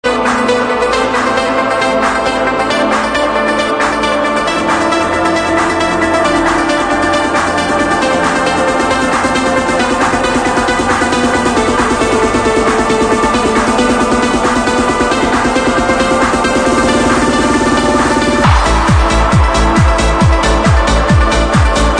one of the best trance anthems ever..